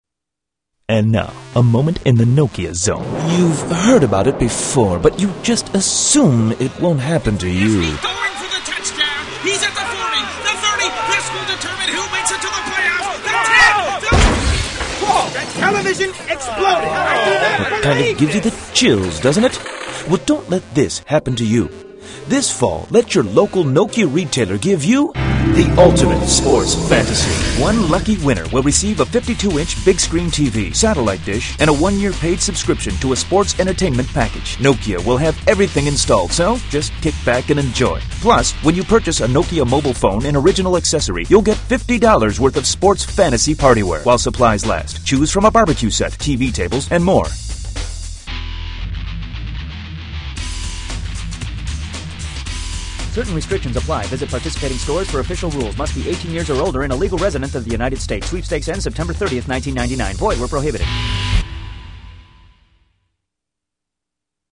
Nokia Radio Commercial